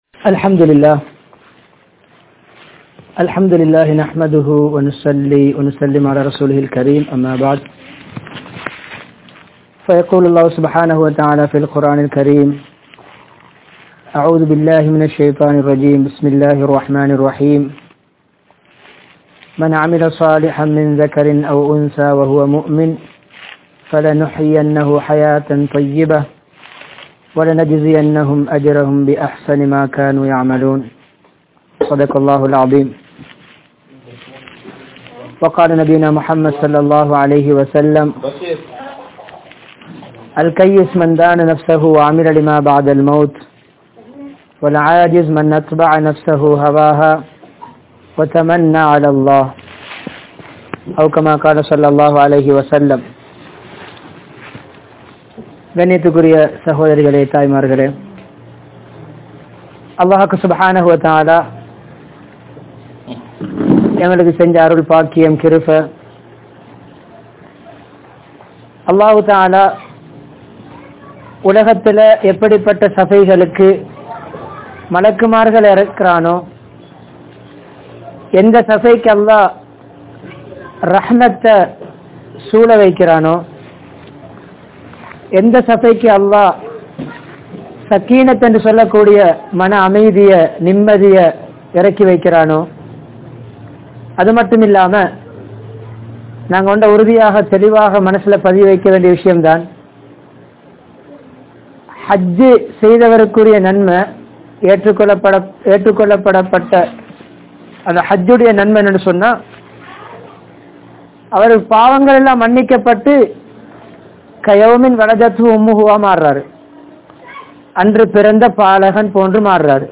Manaivien Kadamaihal (மனைவியின் கடமைகள்) | Audio Bayans | All Ceylon Muslim Youth Community | Addalaichenai
Kinniya, Buhari Jumua Masjith(Markaz)